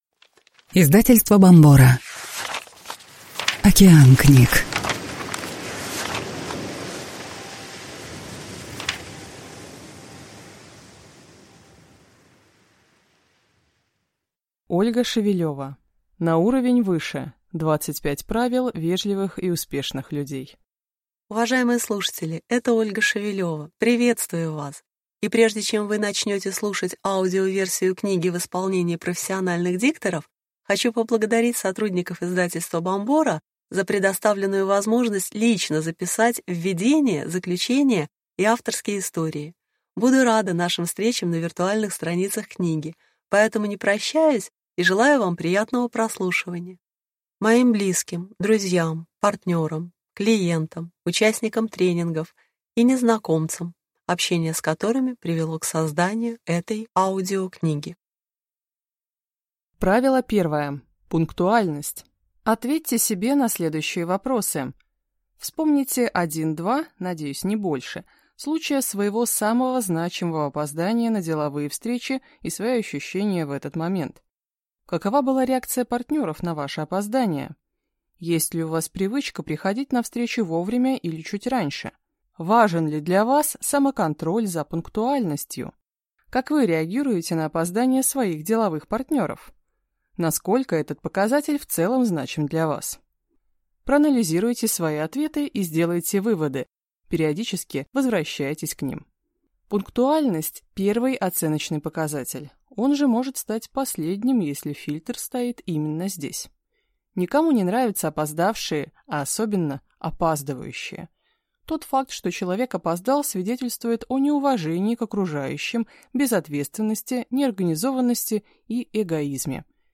Аудиокнига На уровень выше. 25 правил вежливых и успешных людей | Библиотека аудиокниг